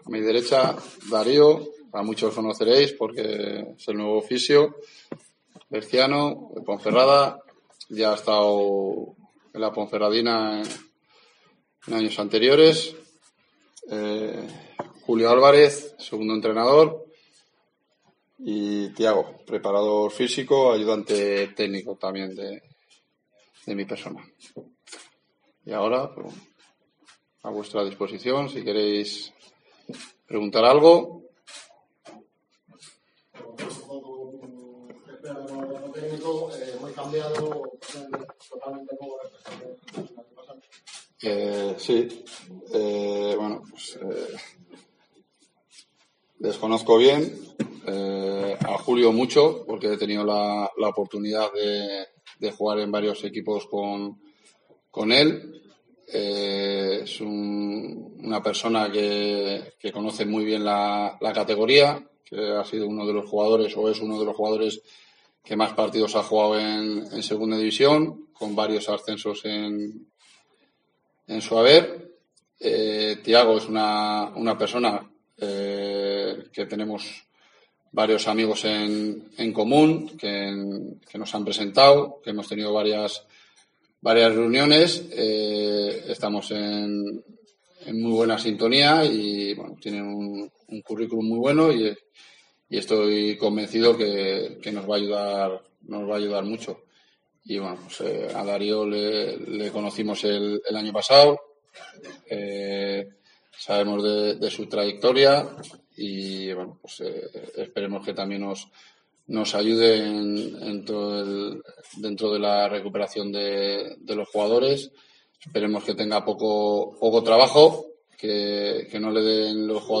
Escucha aquí las declaraciones del nuevo cuerpo técnico de la Deportiva Ponferradina.